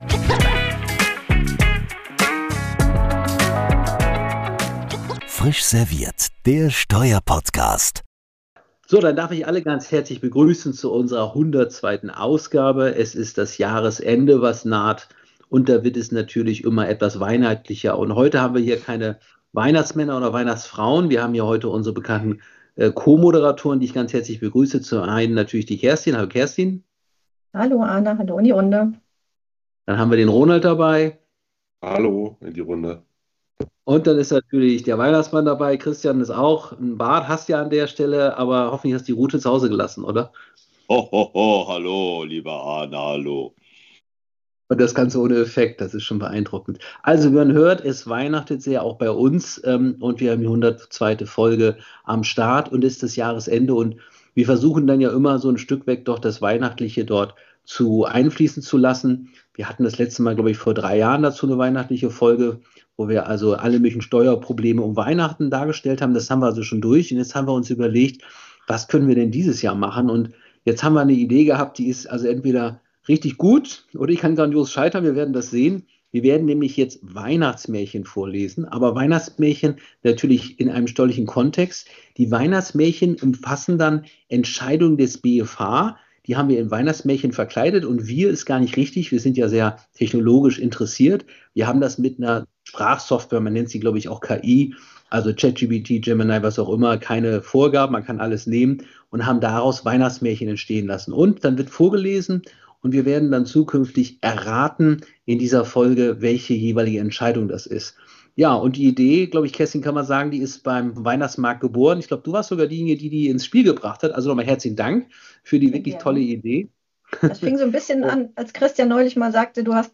Neben Rechtsprechung des BFH sind auch Urteile des EuGH und des BVerfG dabei. Nachdem jeder sein Märchen vorgelesen hat, versuchen die anderen zu erraten, um welches Urteil es sich handelt.